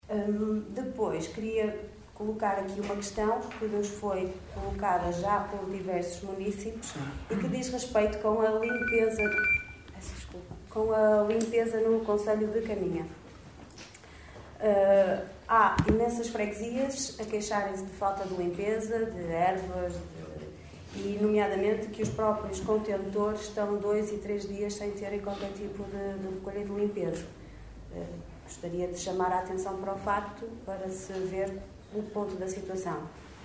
Declarações na última reunião do executivo.